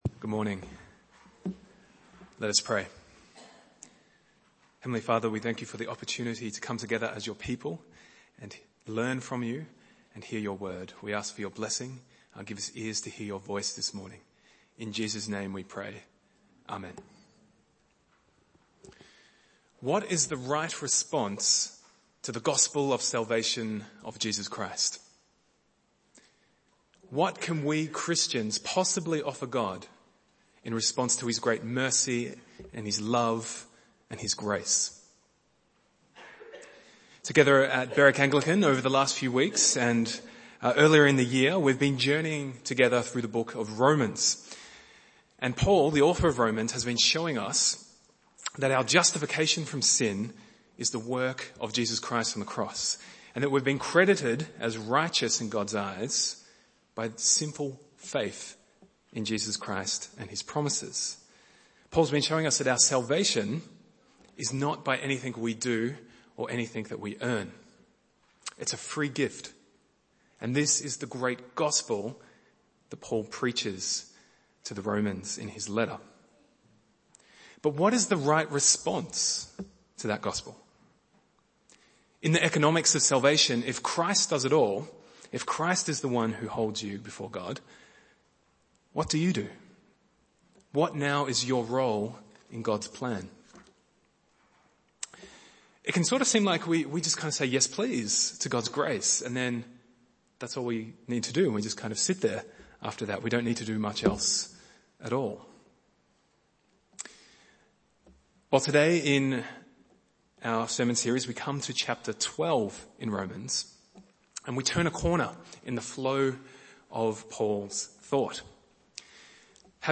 Bible Text: Romans 12:1-21 | Preacher